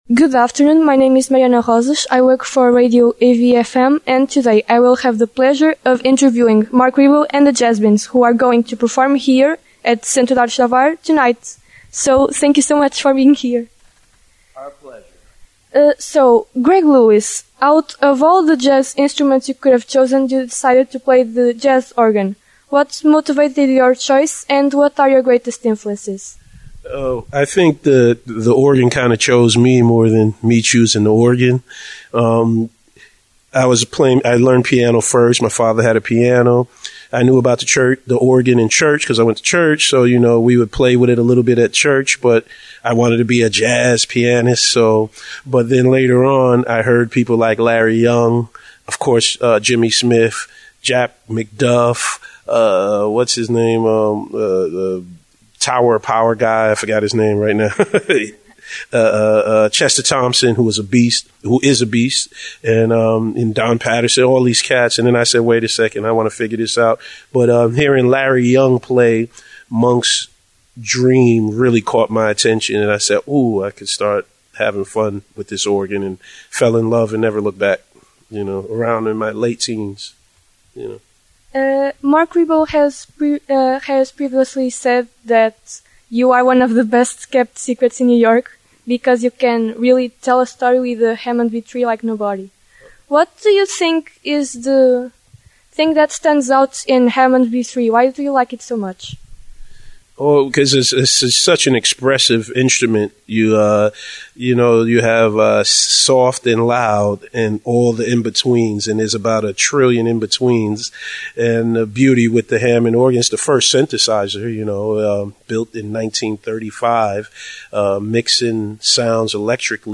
Especial Entrevista